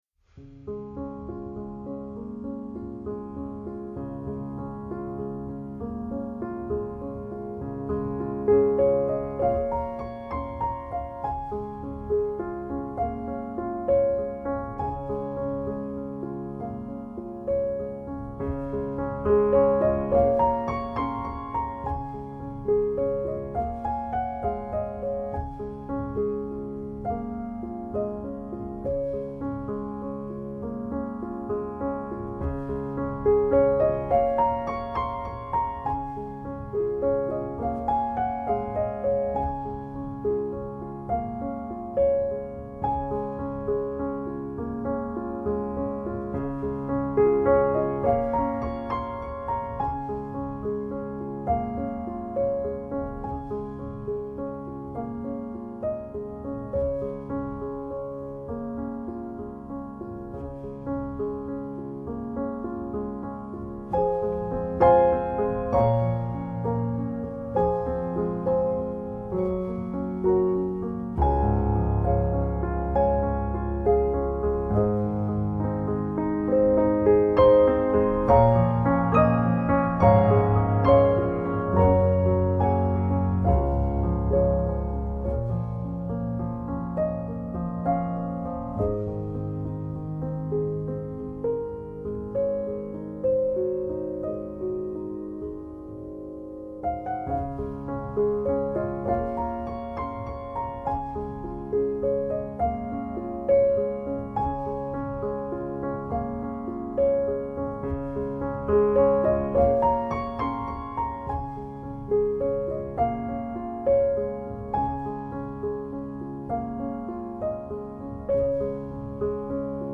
音乐风格: Instrumental / Piano / New Age / Easy Listening